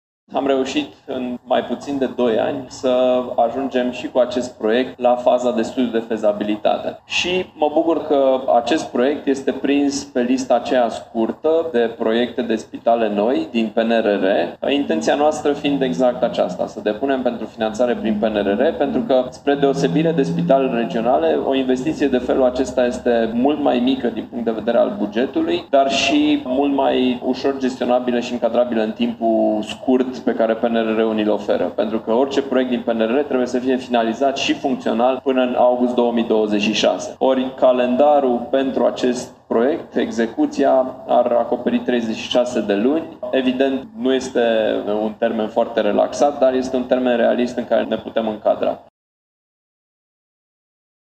Potrivit primarului, Allen Coliban, această investiție ar putea fi realizată prin Planul Național de Redresare și Reziliență: